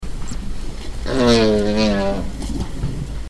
The multipurpose trunk can also be used to make noise, loud noise!
elephant-nasal-trumpet.mp3